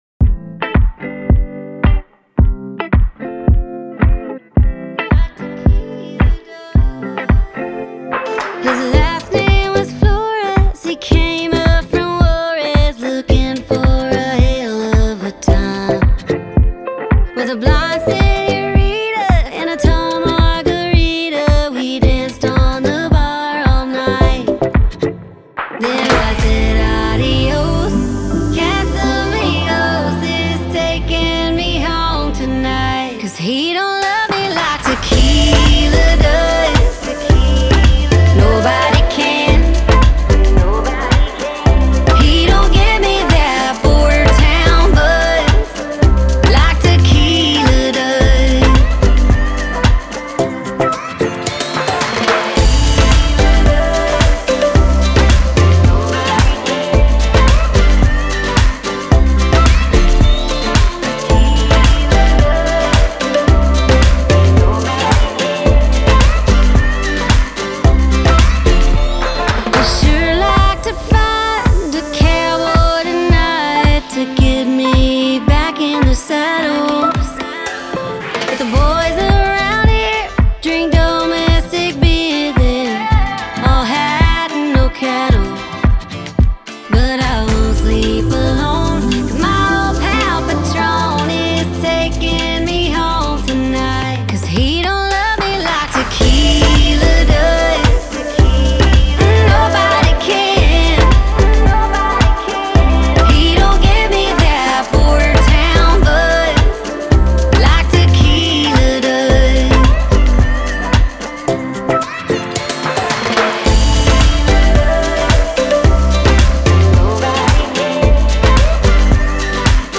это энергичный трек в жанре кантри с элементами поп и EDM